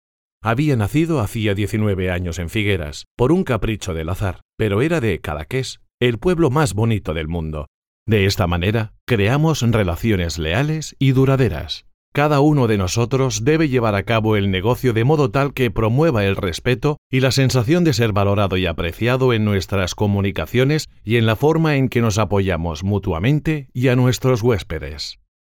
kastilisch
Sprechprobe: Industrie (Muttersprache):